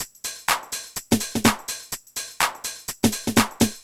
Index of /musicradar/retro-house-samples/Drum Loops
Beat 11 No Kick (125BPM).wav